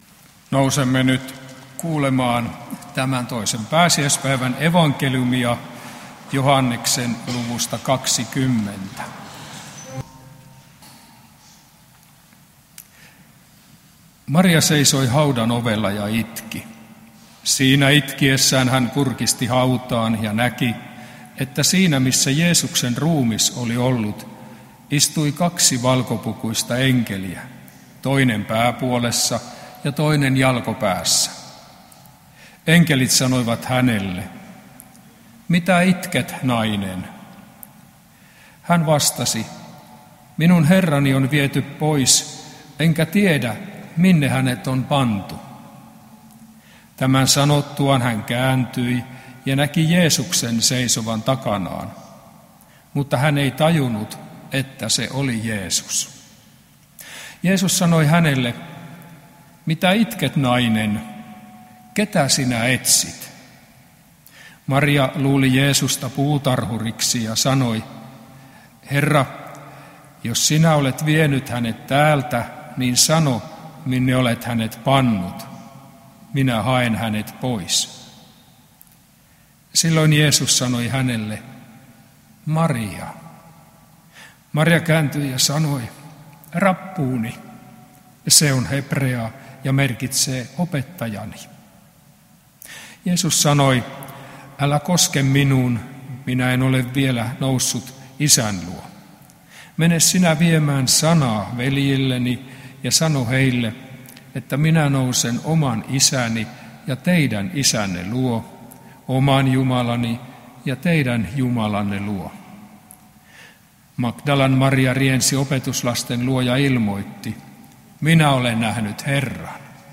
saarna Kokkolassa II pääsiäispäivänä Tekstinä Joh. 20:11–18